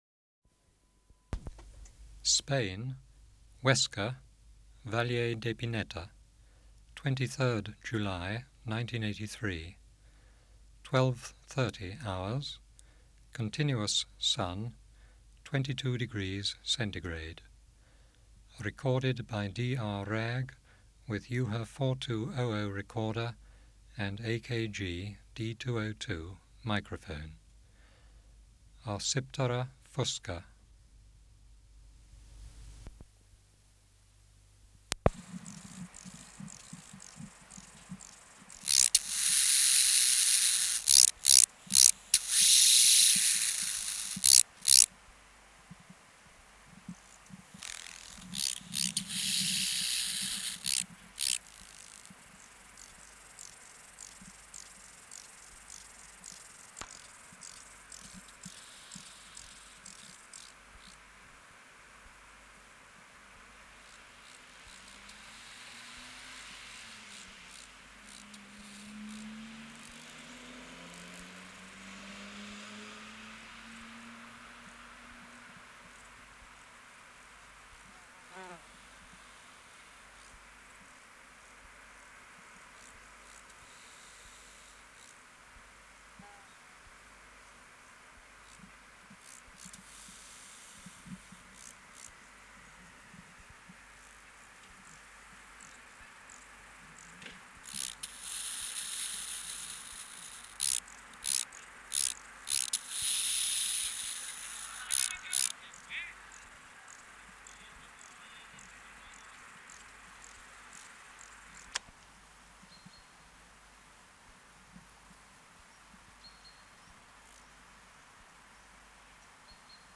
Project: Natural History Museum Sound Archive Species: Arcyptera (Arcyptera) fusca
Reference Signal: 1 kHz for 10 s
Air Movement: Intermittent breeze
Extraneous Noise: Other conspecific males in background
Microphone & Power Supply: AKG D202 (LF circuit off) Windshield: AKG W10